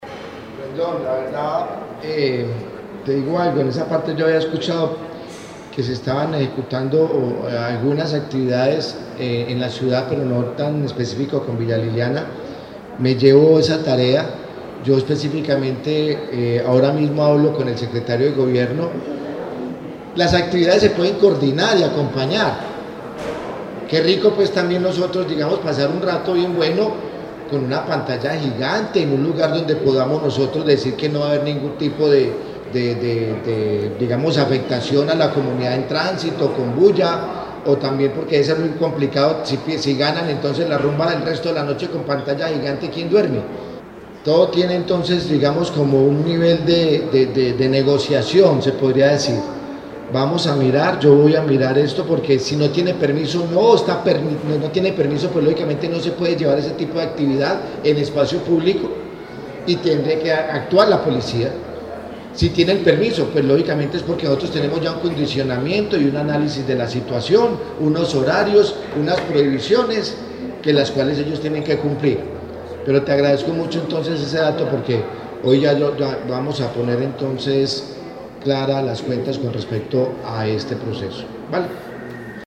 Audio de: Alcalde de Armenia, Quindío, José Manuel Ríos Morales
Alcalde-de-Armenia-Jose-Manuel-Rios-Morales-y-final-del-futbol-colombiano.mp3